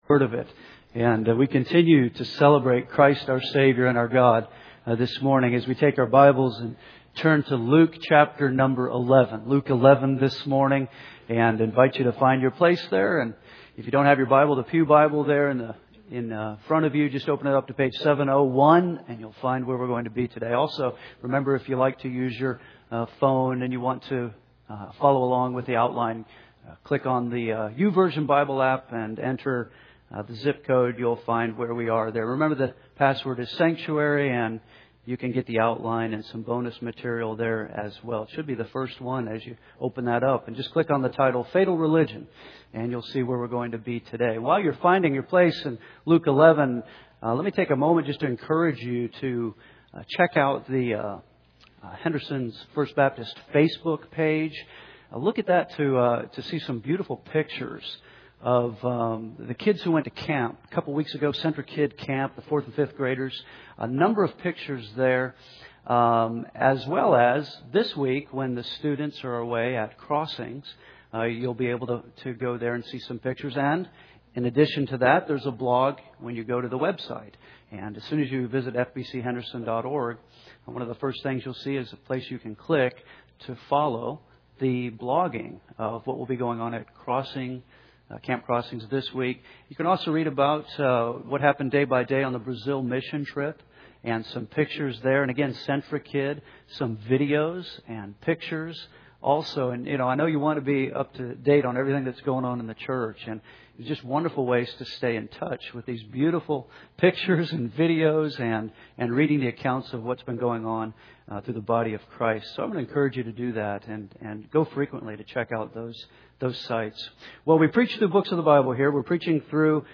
Click on today’s sermon title “Fatal Religion.”